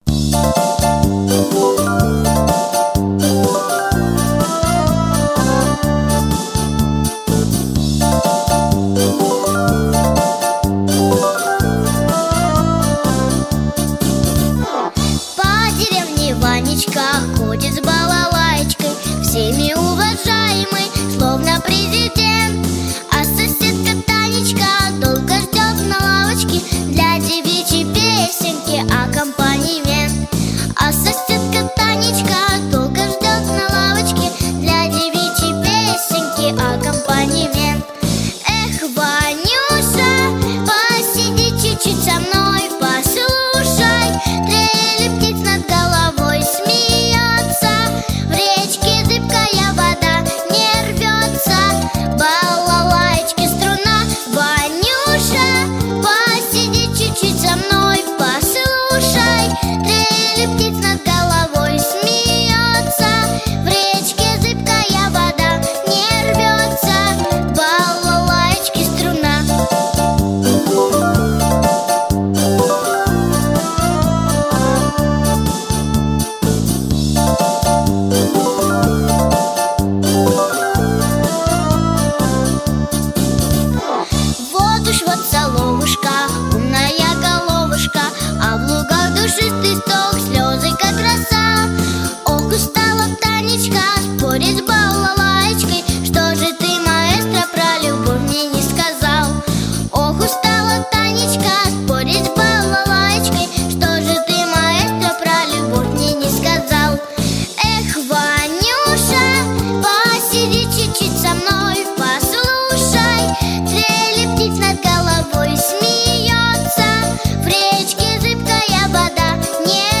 • Категория: Детские песни
народный мотив, балалайка